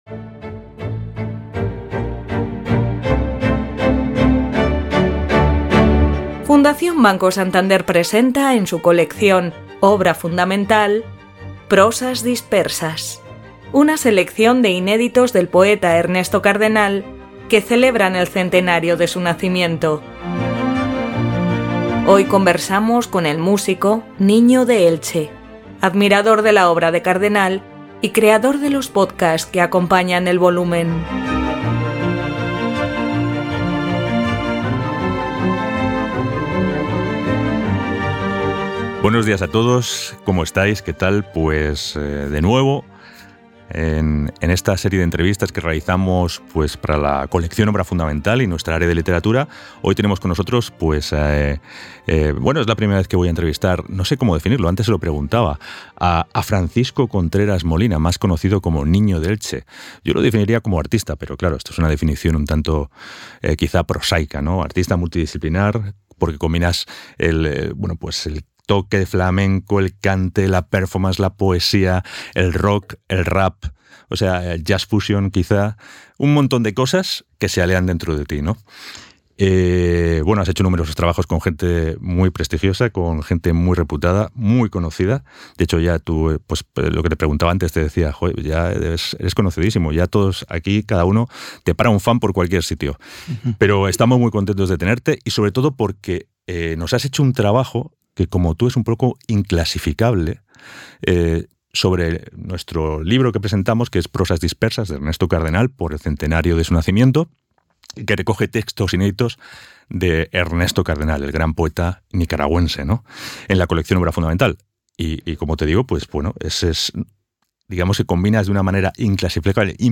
Entrevista NinodeElche.mp3